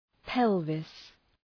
Προφορά
{‘pelvıs}